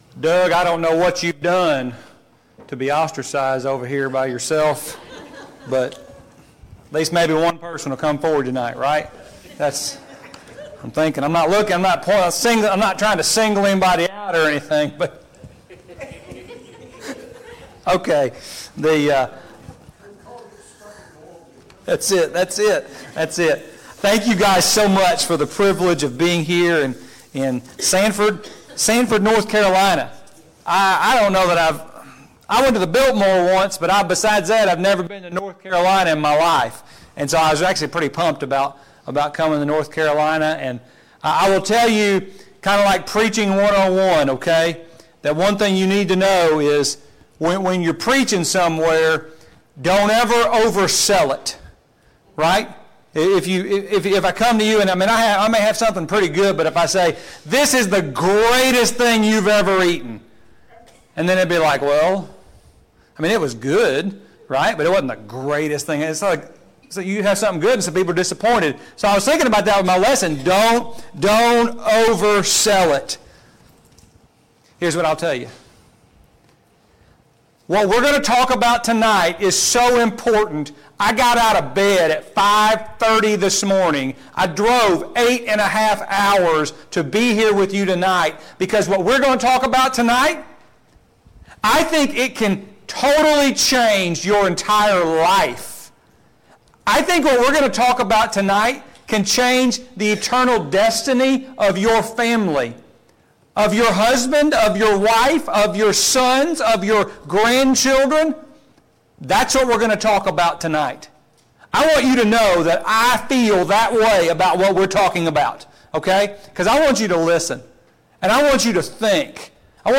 Service Type: Gospel Meeting Topics: The Family , Worship